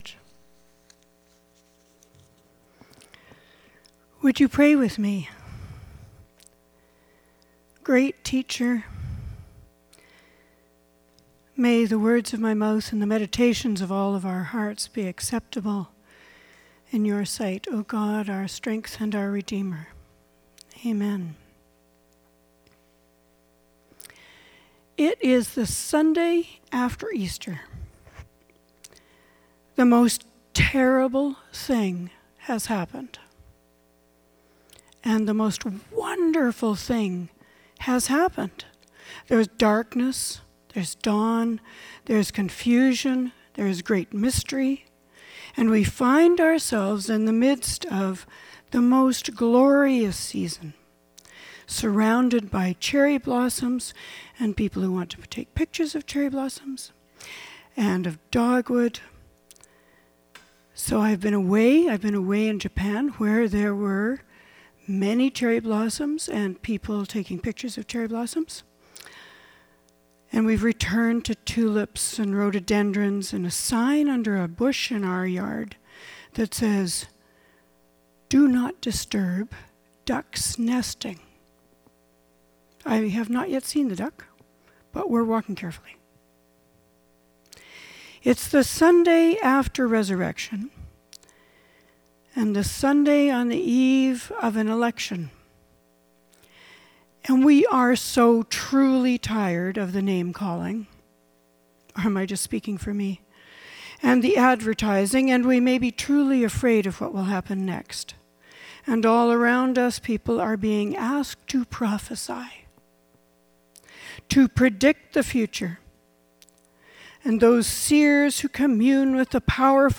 Sermons | Gilmore Park United Church